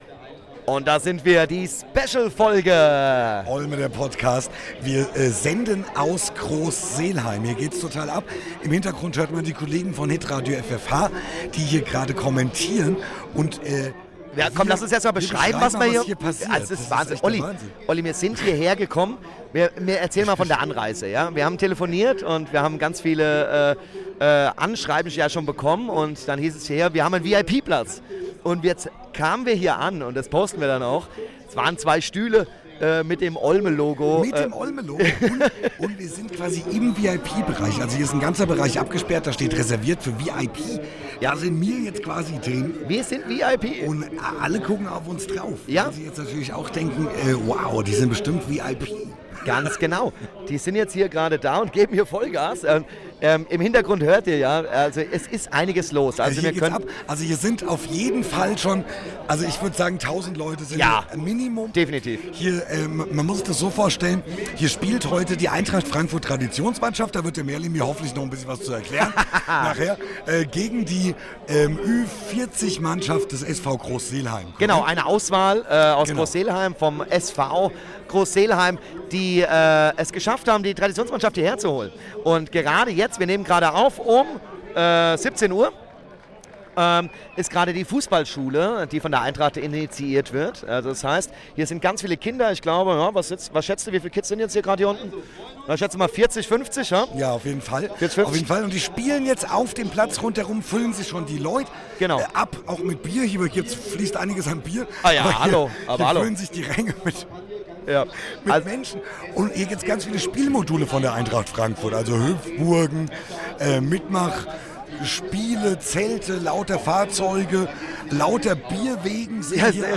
Spezialfolge vom Spielfeldrand!